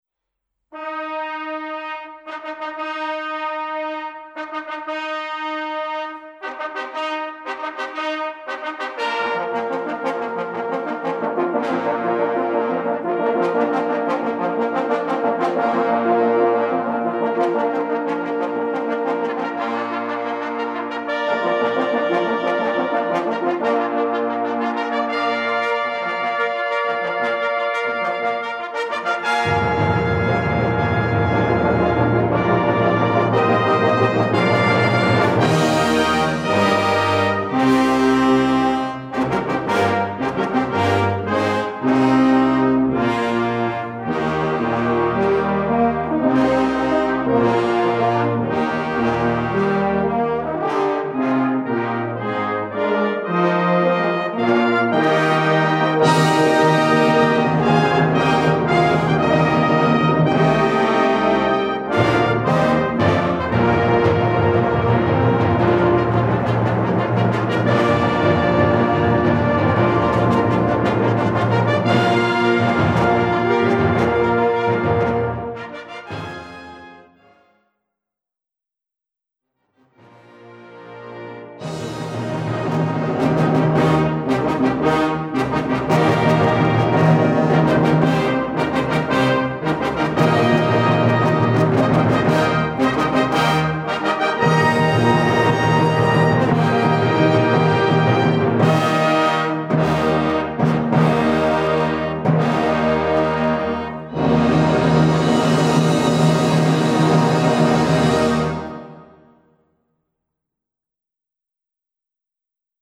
Gattung: Eröffnungswerk
Besetzung: Blasorchester